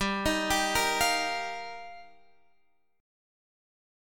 Gm7#5 chord